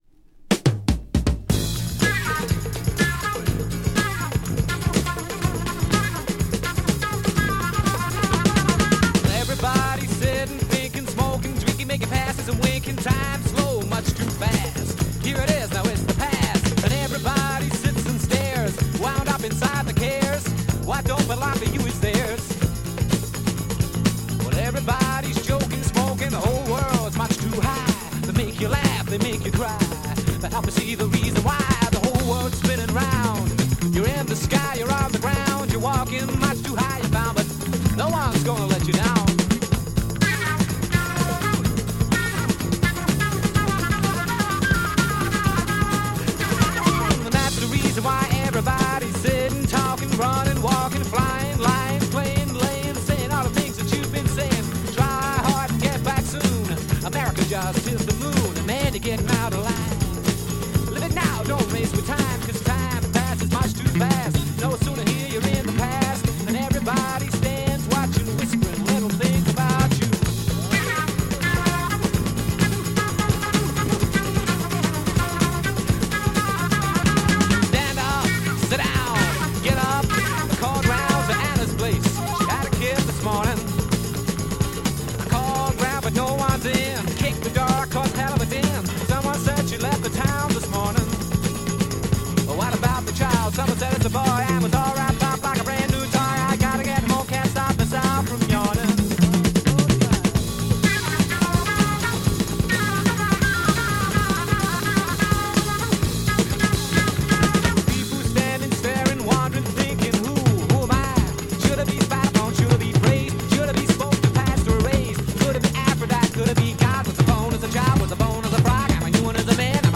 UK Folk PsychFunk French Album
amazing funky psych folk dancer